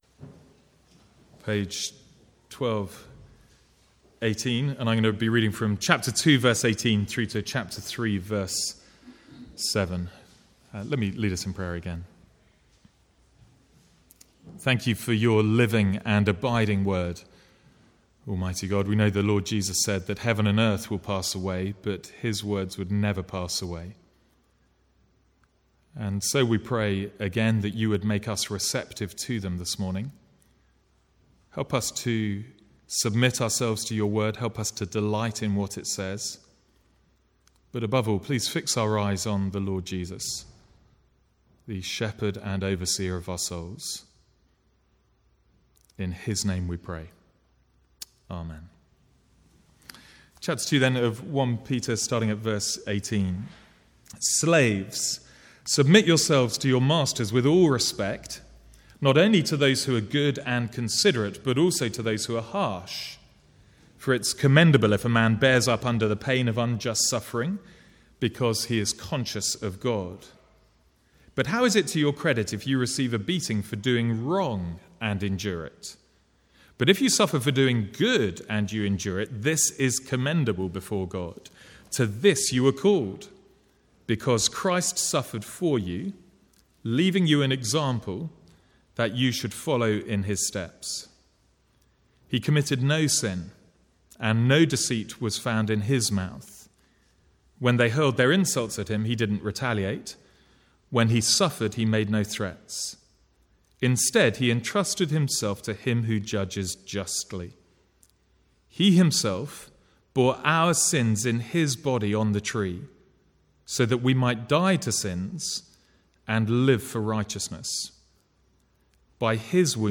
From the Sunday morning series in 1 Peter.